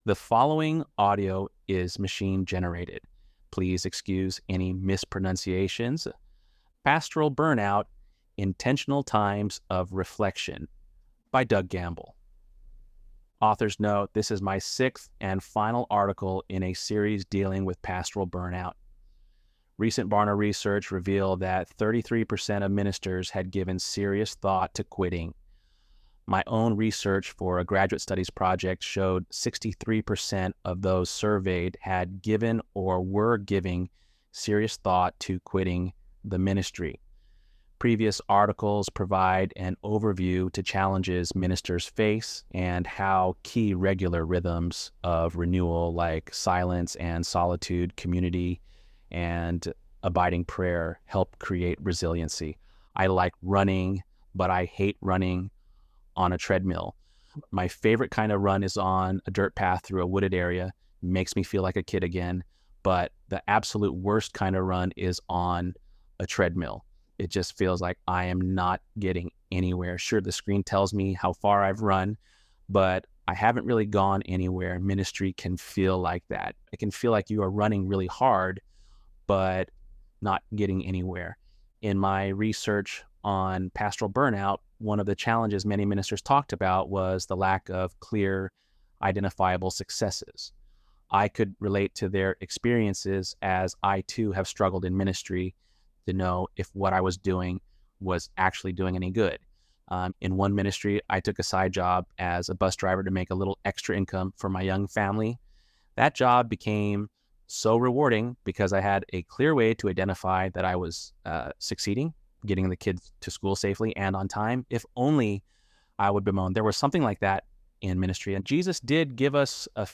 ElevenLabs_Untitled_Project-12.mp3